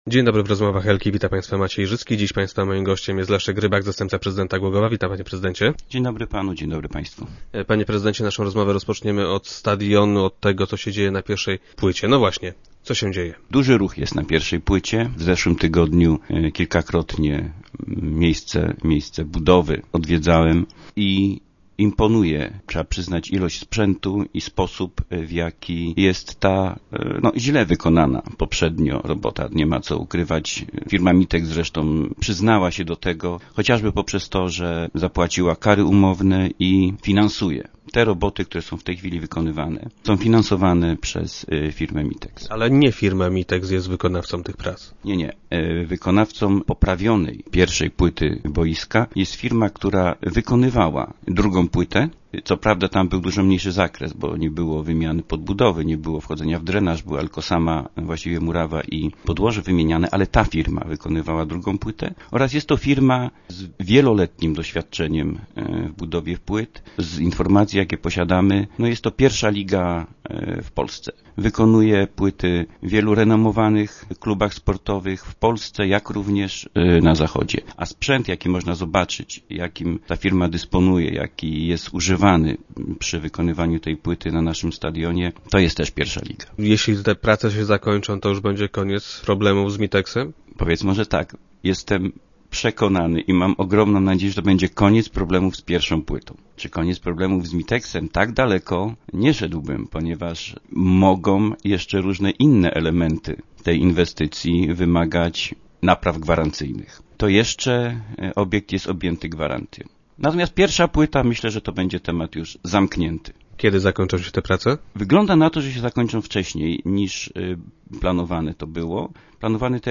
- Jestem pod wrażeniem tempa prac i wykorzystanego sprzętu - powiedział nam Leszek Rybak, zastępca prezydenta i gość dzisiejszych Rozmów Elki.